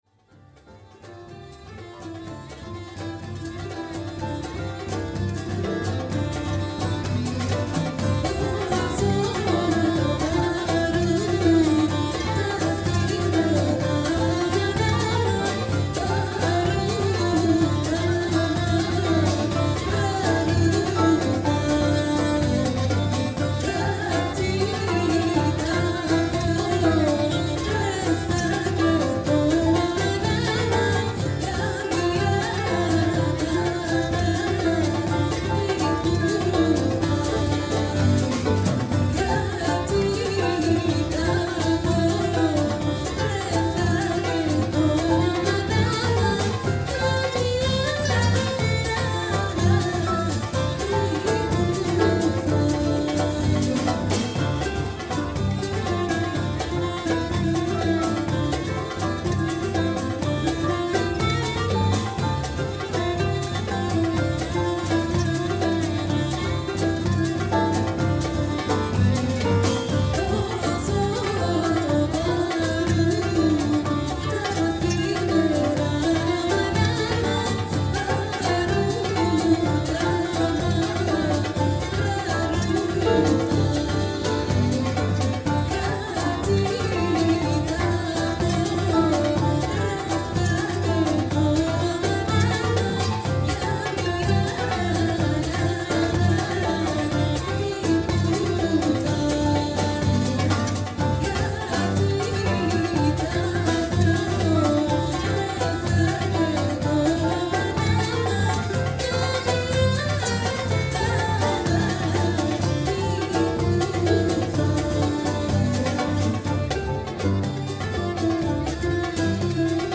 saxophone soprano, clarinette, sanza, gong, cloches
harpe
violon
darbouka, tambourin
contrebasse, lotar
batterie, cloche